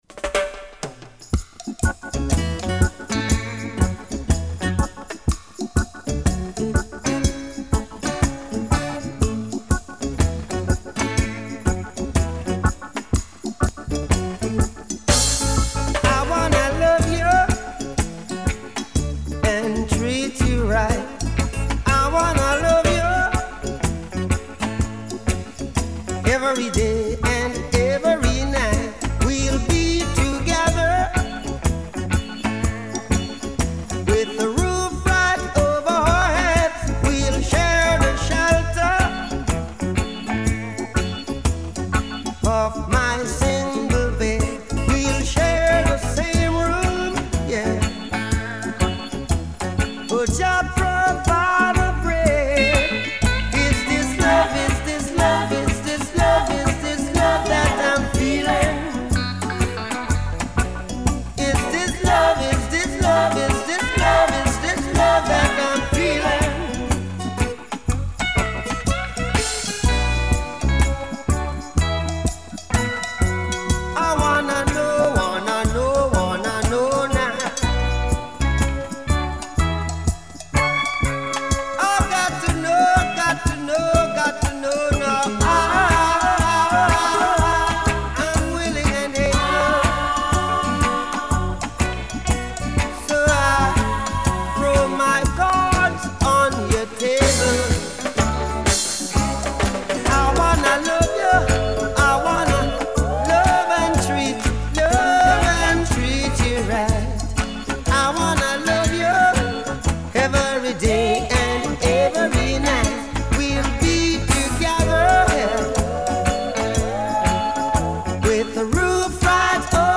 سبک موسیقی رگی